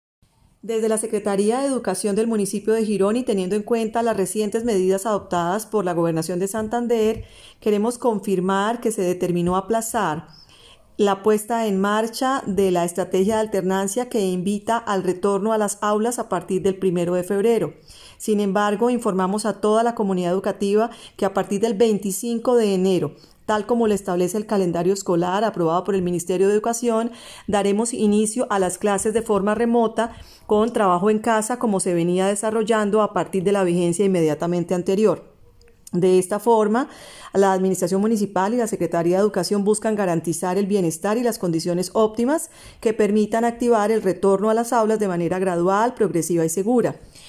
Luisa Montero - Secretaria de Educación.mp3